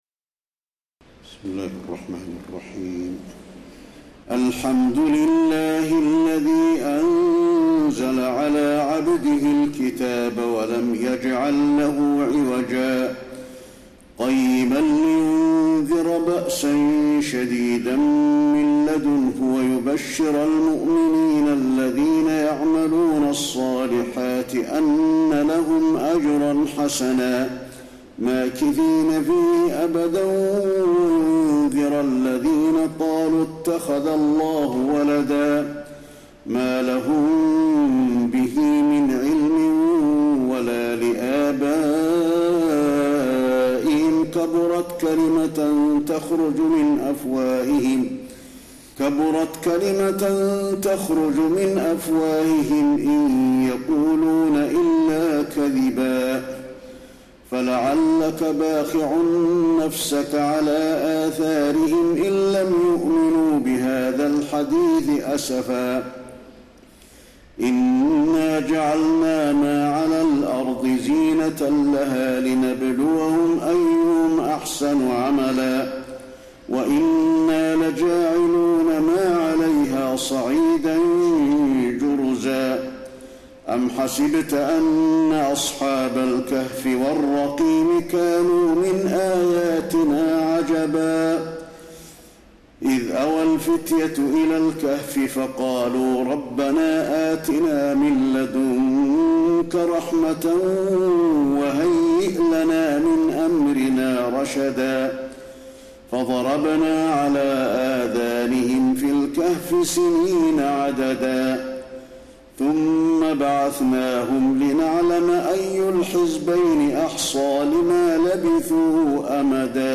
تراويح الليلة الخامسة عشر رمضان 1432هـ سورة الكهف Taraweeh 15 st night Ramadan 1432H from Surah Al-Kahf > تراويح الحرم النبوي عام 1432 🕌 > التراويح - تلاوات الحرمين